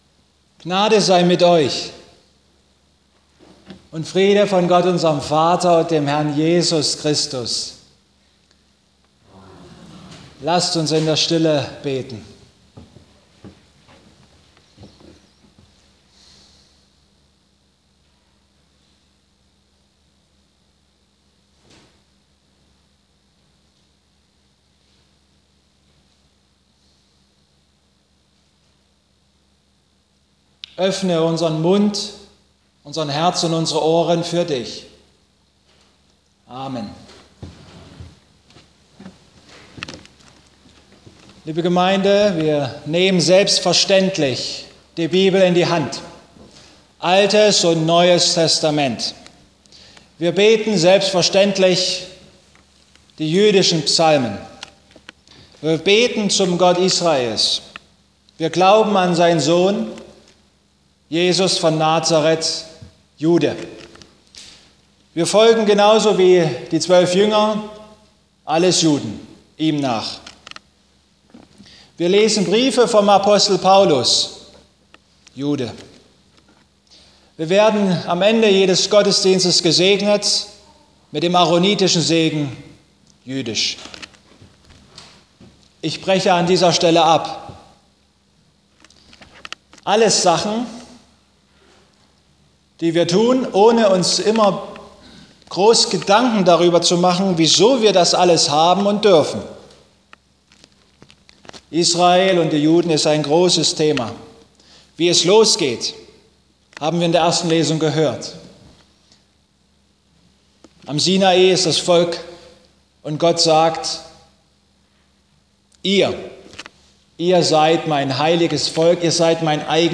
Röm 11,25-32 Gottesdienstart: Abendmahlsgottesdienst Es ist derzeit so heiß wie in Israel.